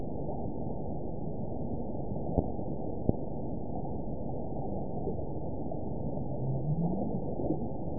event 922876 date 04/30/25 time 16:33:21 GMT (1 month, 2 weeks ago) score 7.40 location TSS-AB01 detected by nrw target species NRW annotations +NRW Spectrogram: Frequency (kHz) vs. Time (s) audio not available .wav